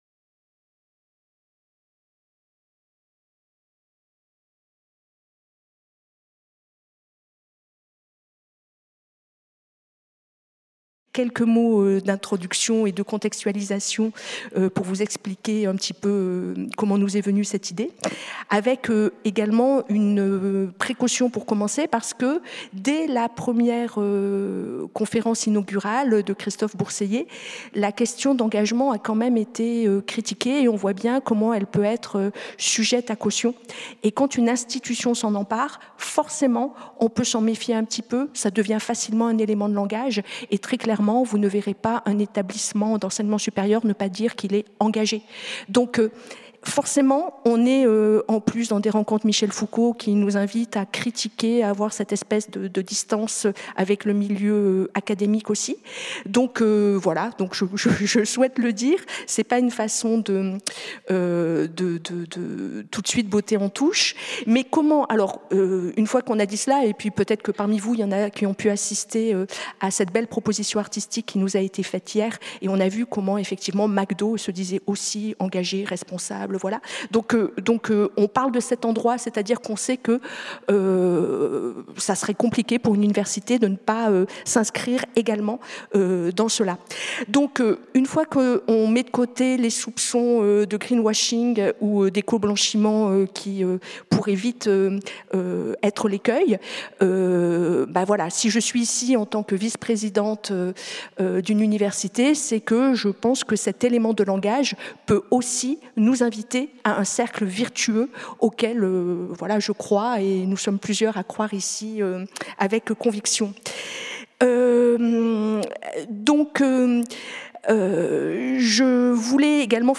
Table ronde